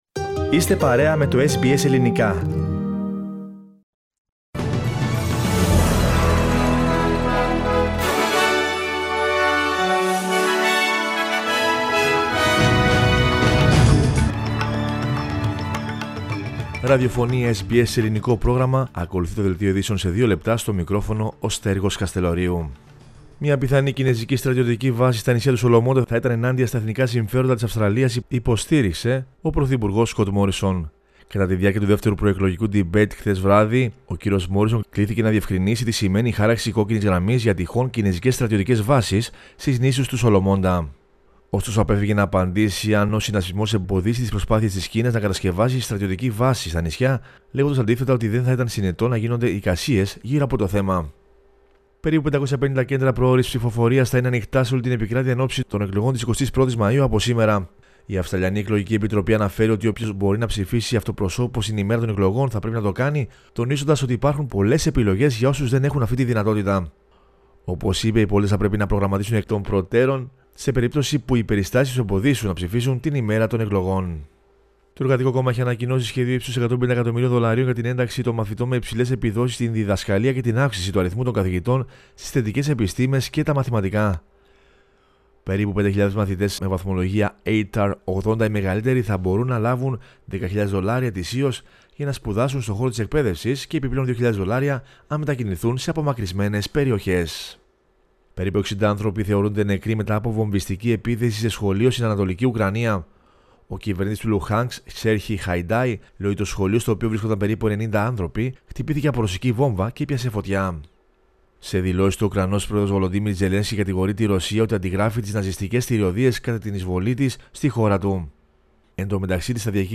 News Flash - Δευτέρα 09.05.22